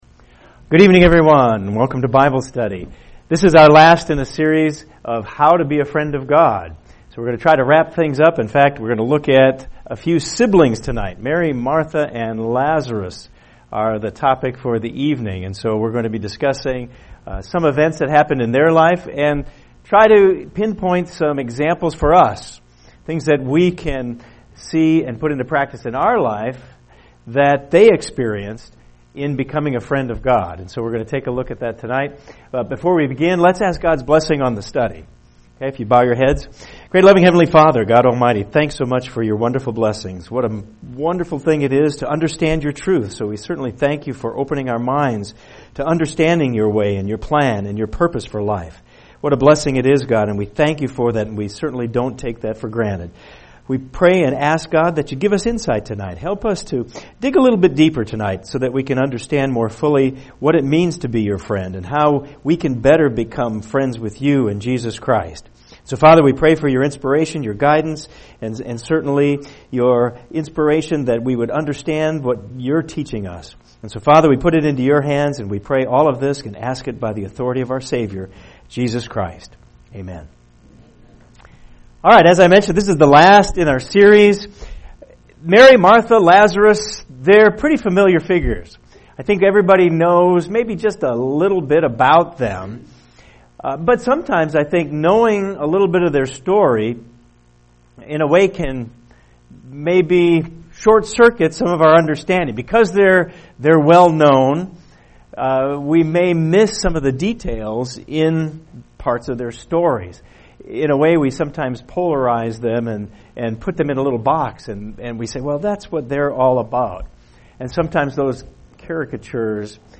This is the fourth part in the Bible study series: How to Be God’s Friend.